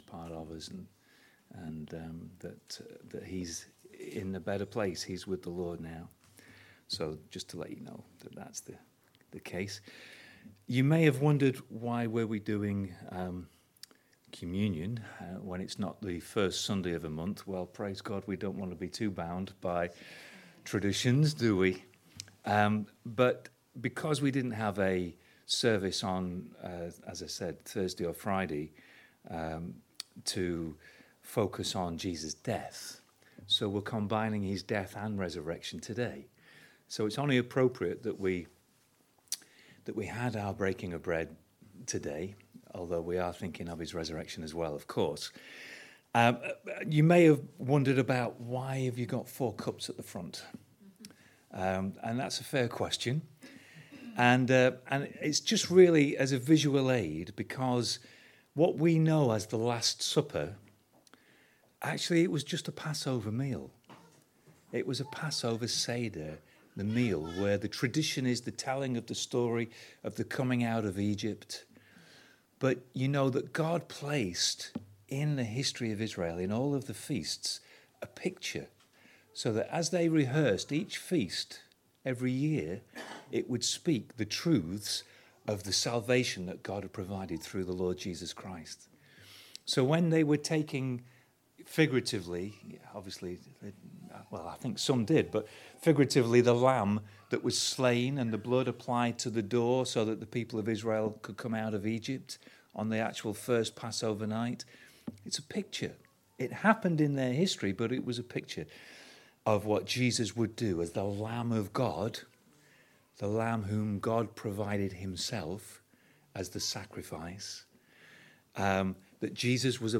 Welcome to the audio from today’s service, below.
(apologies for poor recording quality)